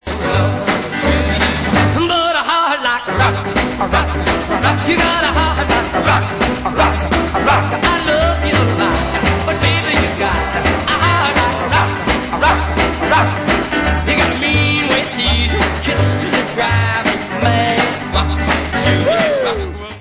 50's rock